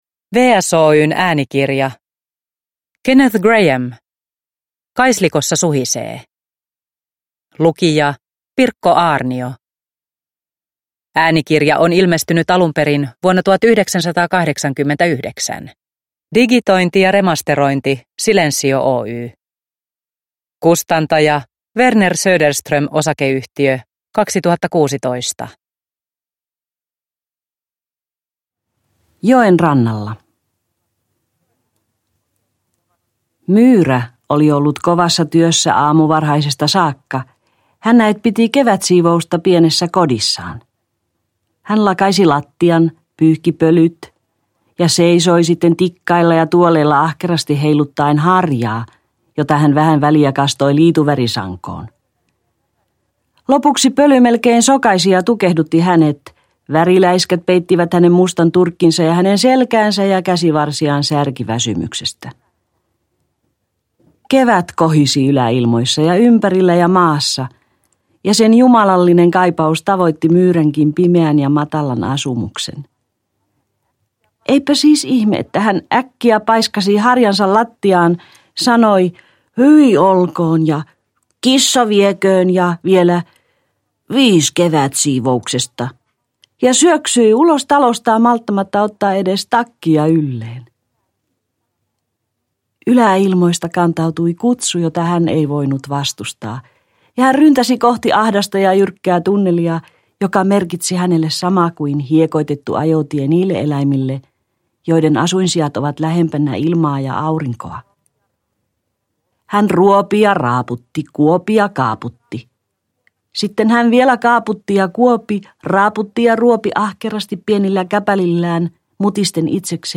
Kaislikossa suhisee – Ljudbok